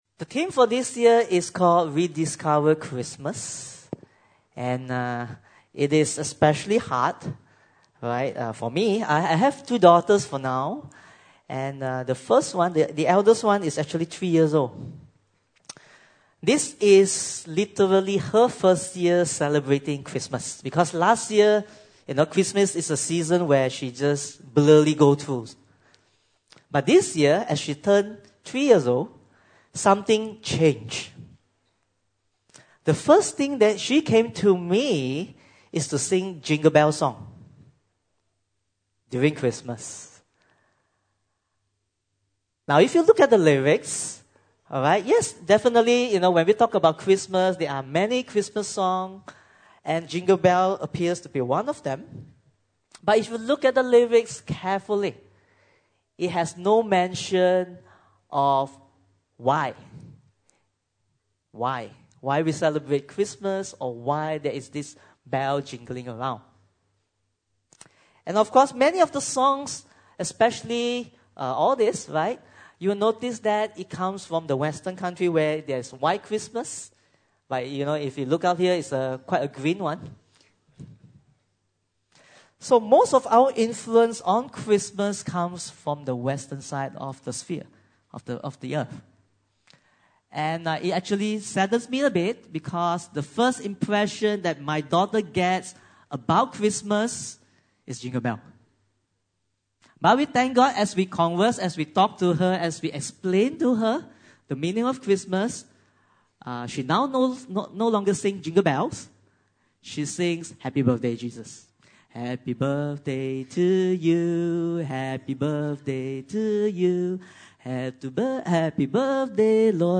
Special Service